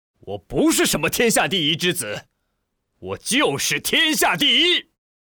为了在常态与变身状态之间形成强烈的戏剧反差，声优为常态下的吕柯选用了年轻、帅气且坚毅的音色，使得其性格变化更加鲜明，增强了角色的立体感。
标签：游戏   年轻   男人   傲气